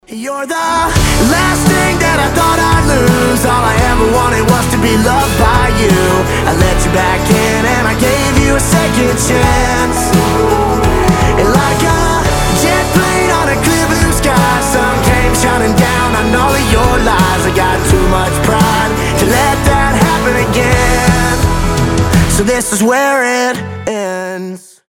рок , гитара , кантри